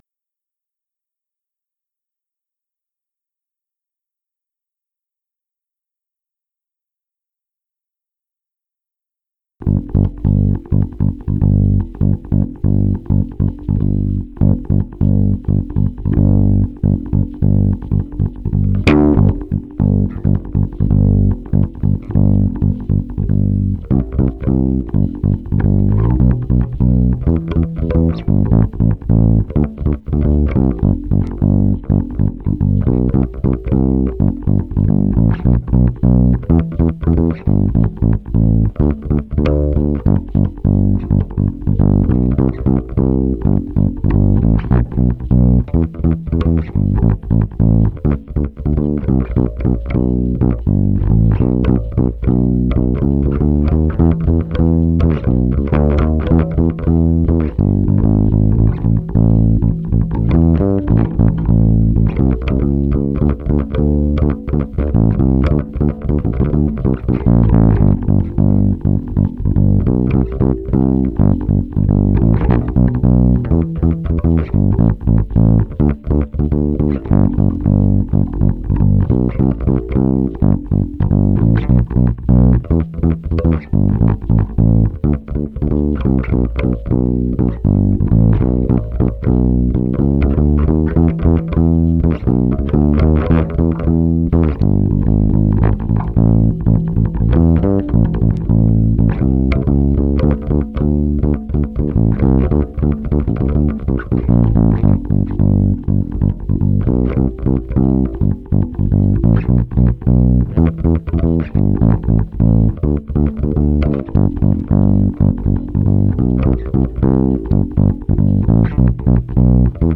basse uniquement